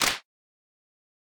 stretchSNAP_UI.mp3